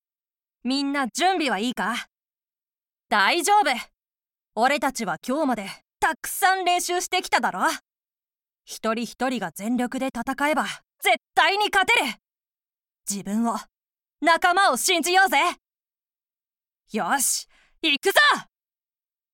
セリフ：10代～20代女性
ナレーション：商品紹介（明るめ）